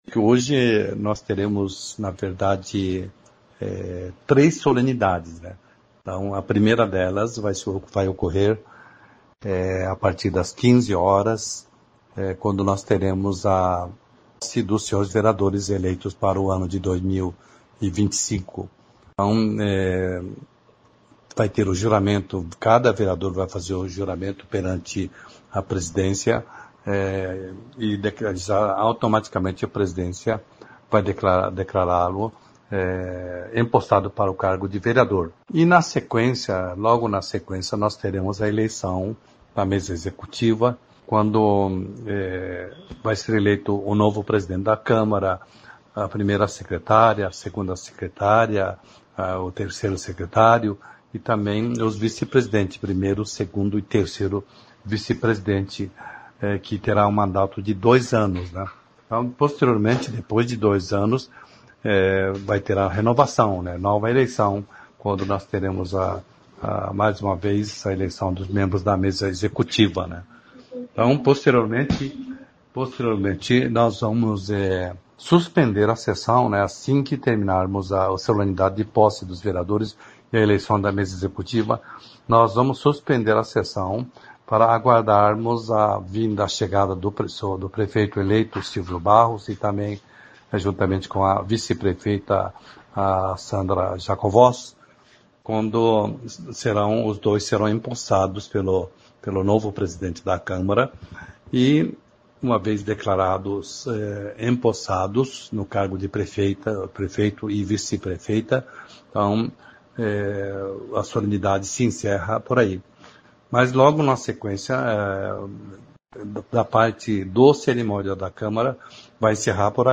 A cerimônia foi realizada nessa quarta-feira (1º) no Teatro Calil Haddad.
Os 23 vereadores declararam o voto no microfone.